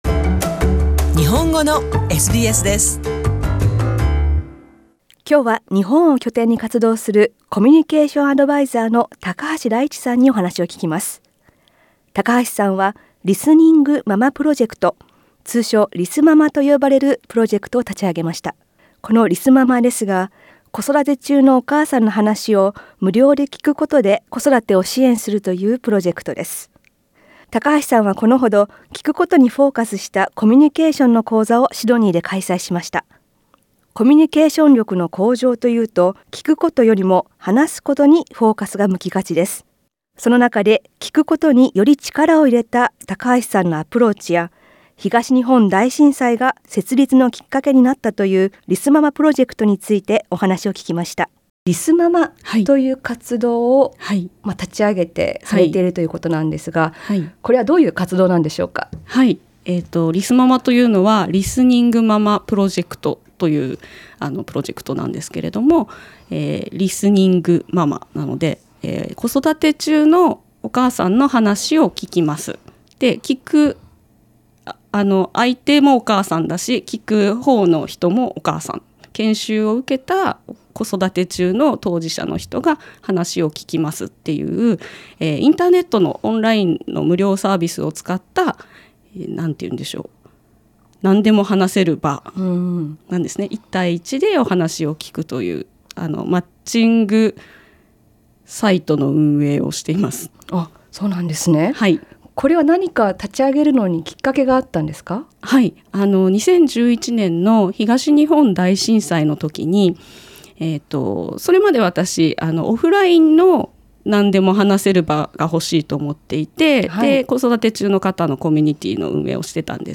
インタビューでは、リスニング・ママプロジェクトについて、そしてコミュニケーションで聞くことにフォーカスした自身のアプローチなどについて聞きました。